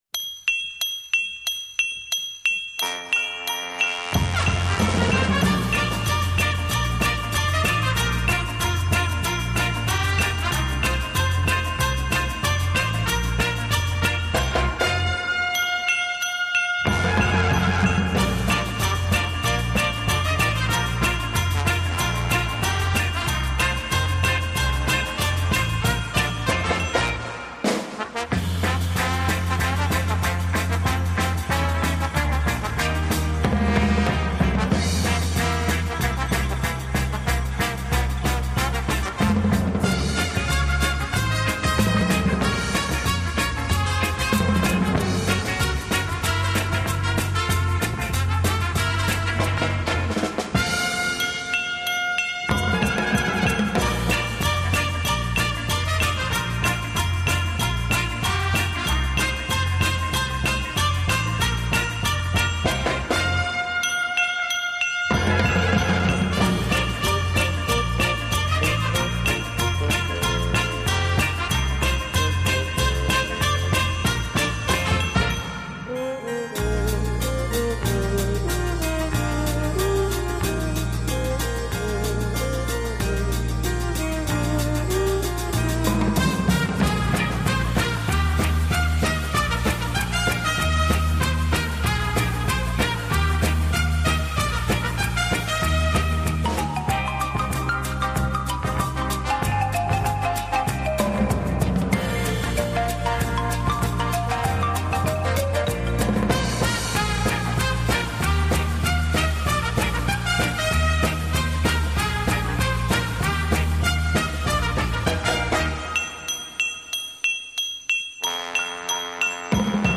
世界三大轻音乐团